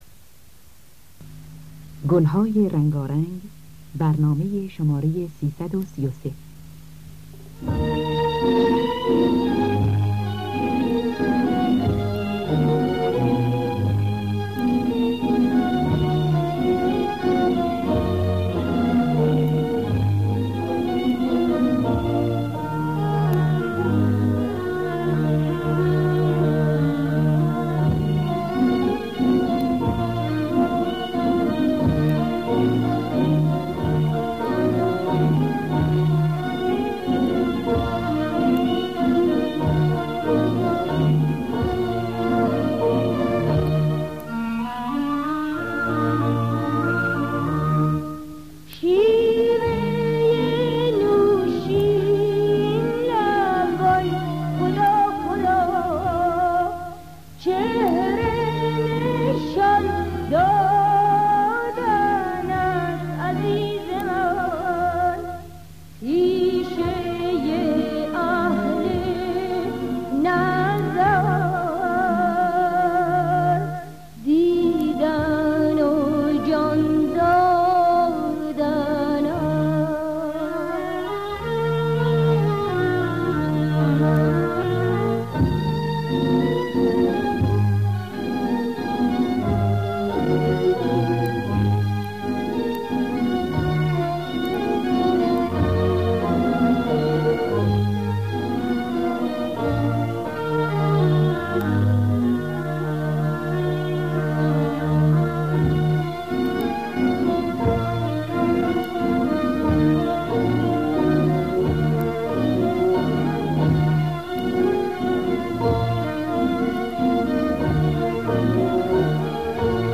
دانلود گلهای رنگارنگ ۳۳۳ با صدای مرضیه، حسین قوامی در دستگاه بیات اصفهان.